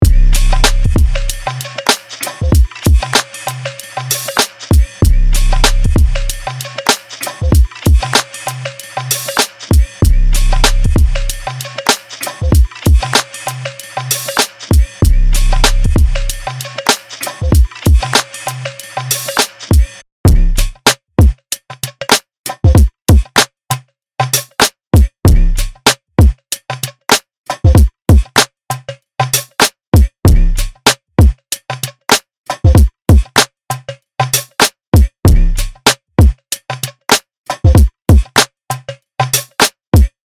Drums | Preset: Tight Kit Glue (DRY→WET)
Articulate-Drums-Tight-Kit-Glue-2.mp3